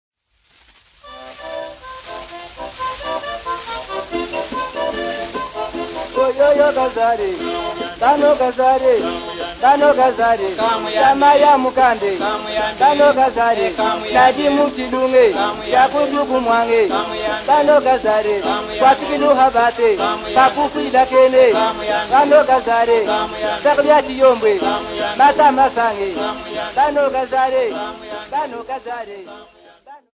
Folk Music
Field recordings
sound recording-musical
Indigenous music